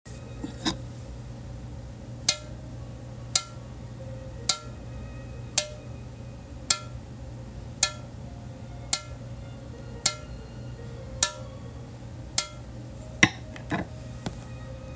We have been calculating dwell time for many years, so below we will associate a frequency (sound) with string bed stiffness so you can hear what “Pop” sounds and feels like!